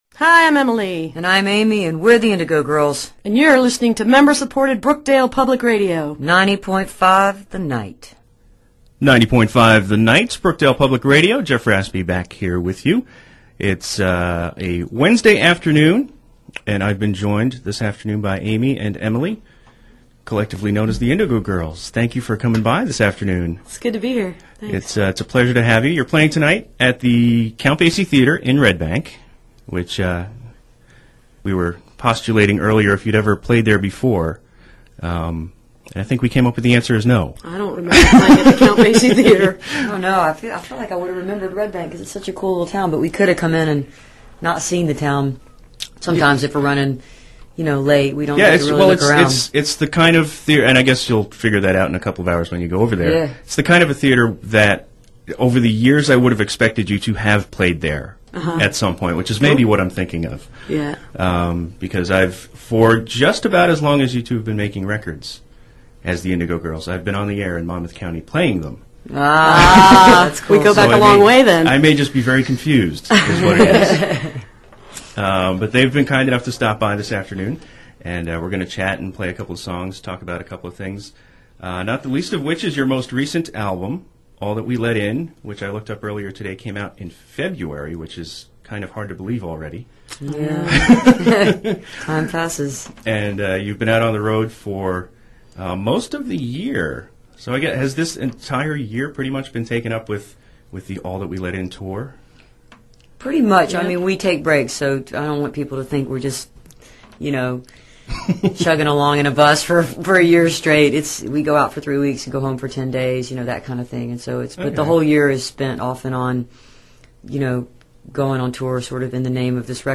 01. interview (7:14)